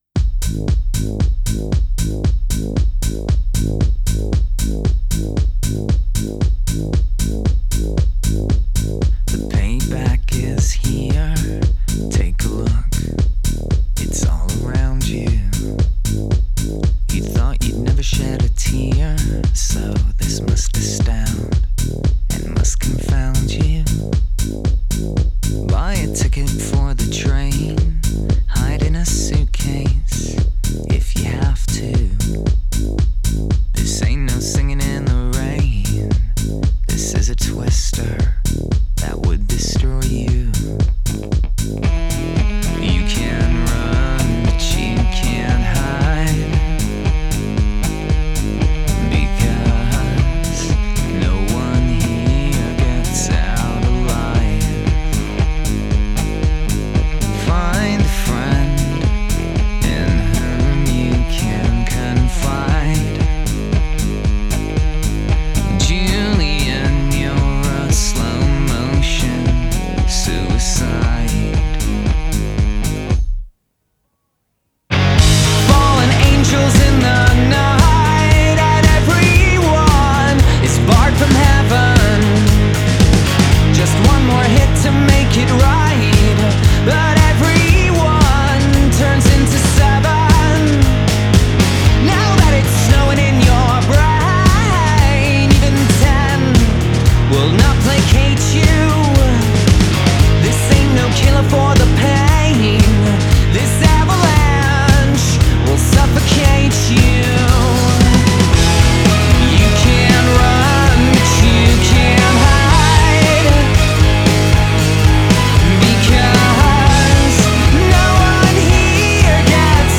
Alternative rock Indie rock Post punk